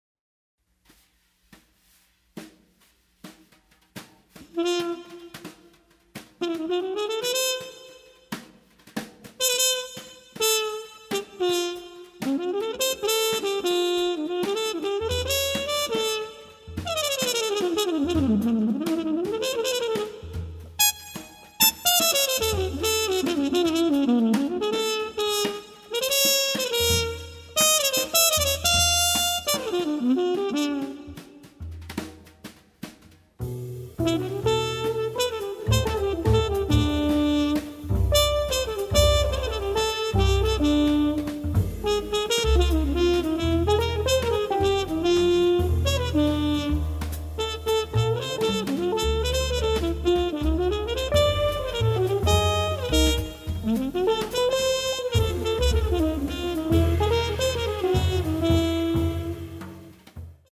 trumpet, keyboards